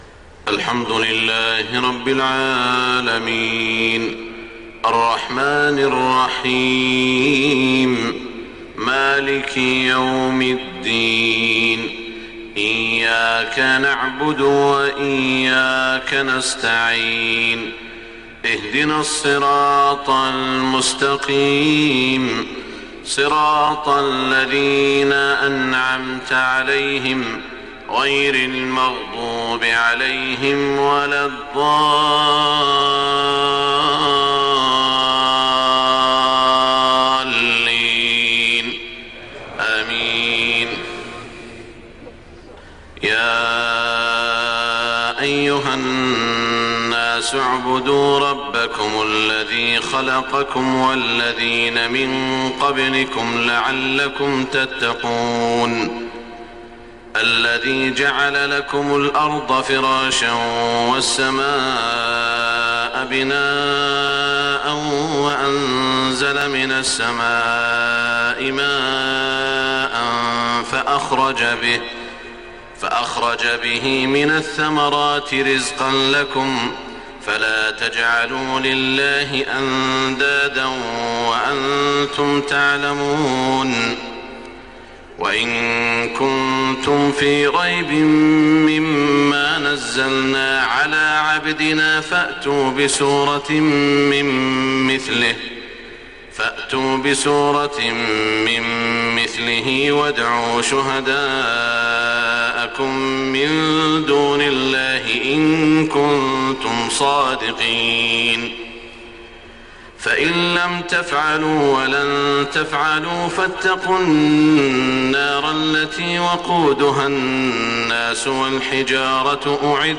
صلاة الفجر 5 محرم 1429هـ سورة البقرة > 1429 🕋 > الفروض - تلاوات الحرمين